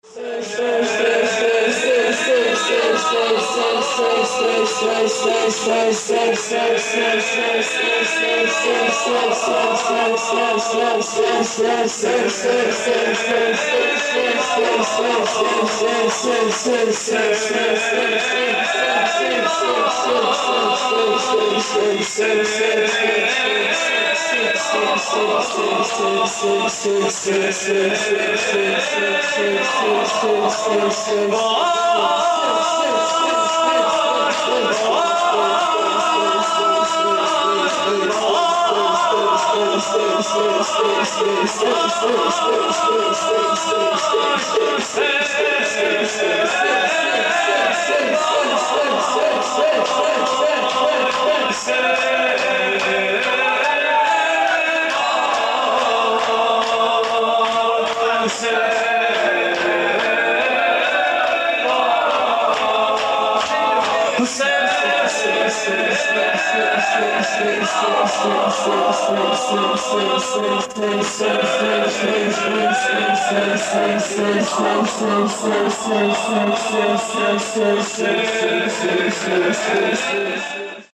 نغمه: حسین وای
مراسم عزاداری اربعین حسینی